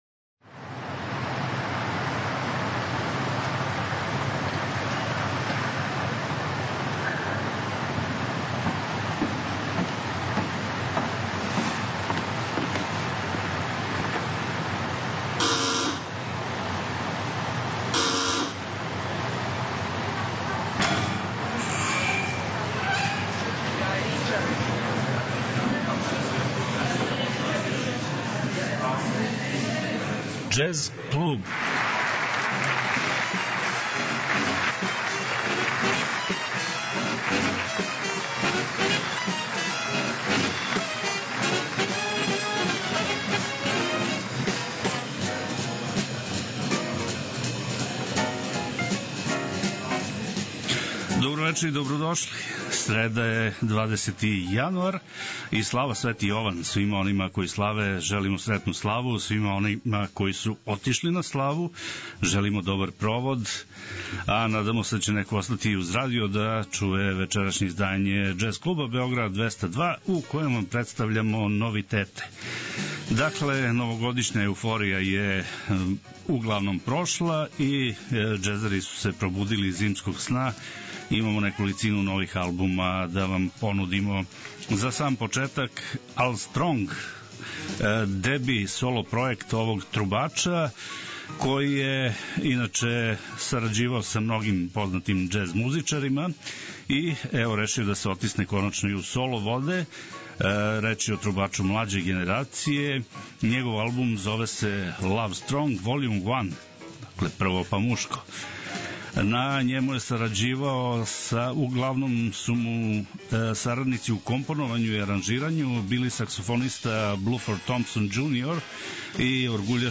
преузми : 14.31 MB Џез клуб Autor: Београд 202 Џез актуелности, нова издања, албуми и аутори, концерти и џез историја.